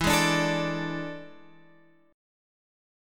E11 chord {12 11 12 x 10 10} chord